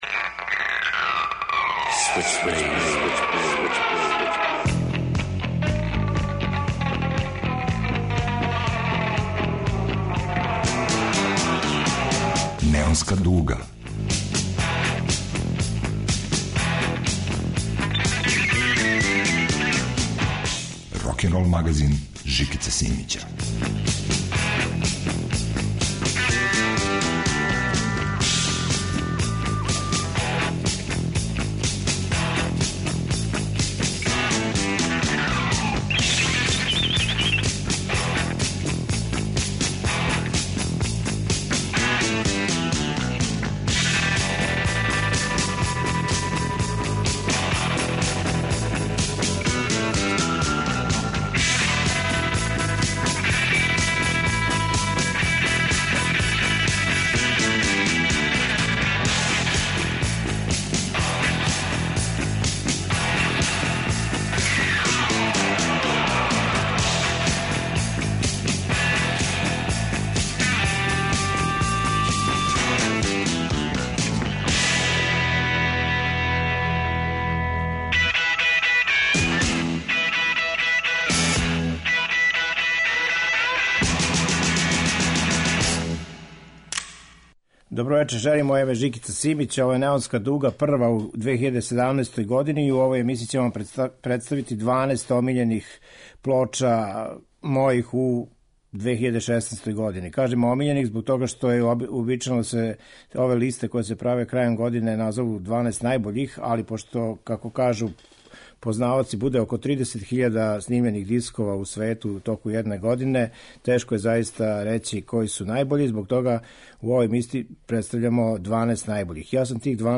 Рокенрол као музички скор за живот на дивљој страни. Вратоломни сурф кроз време и жанрове.